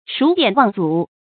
注音：ㄕㄨˇ ㄉㄧㄢˇ ㄨㄤˋ ㄗㄨˇ
數典忘祖的讀法